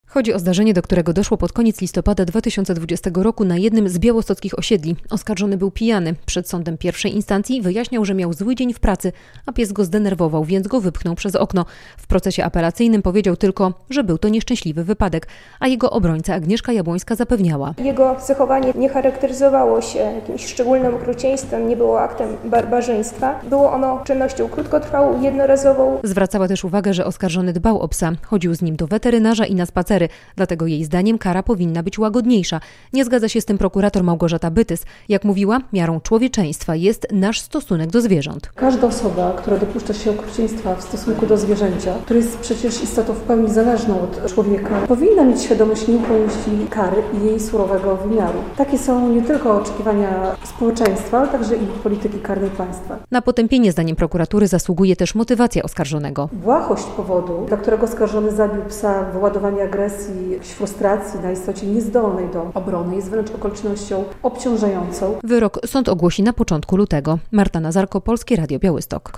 Przed białostockim sądem zakończył się proces apelacyjny mężczyzny, który wyrzucił psa przez okno z 8 piętra - relacja